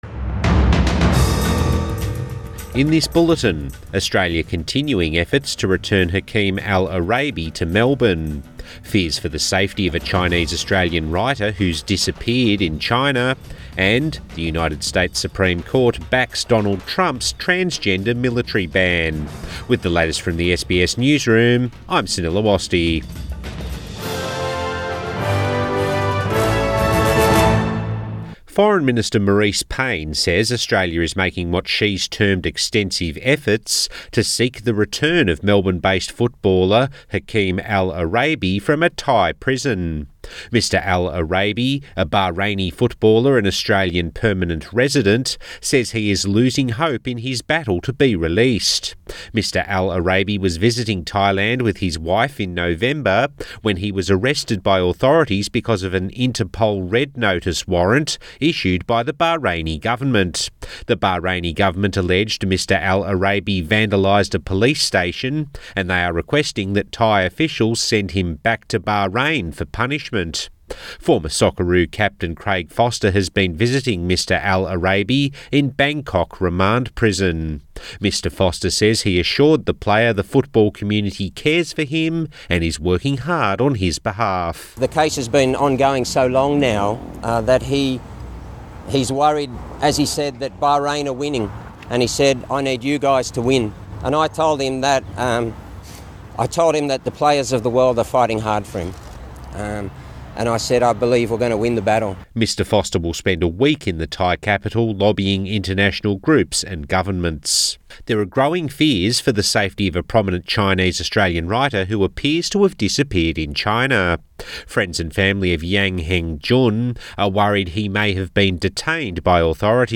Midday Bulletin 23 January